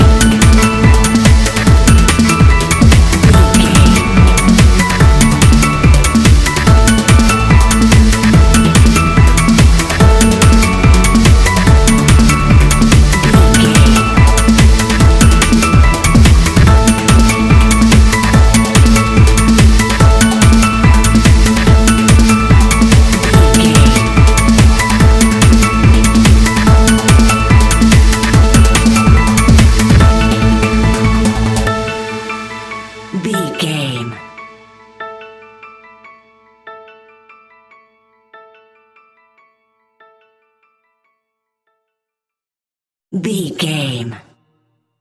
Ionian/Major
Fast
driving
energetic
uplifting
hypnotic
drum machine
piano
synthesiser
acid house
uptempo
synth leads
synth bass